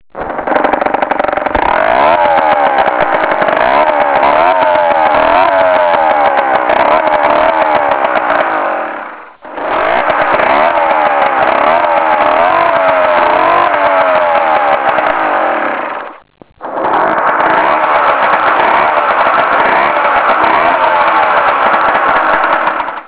moped.au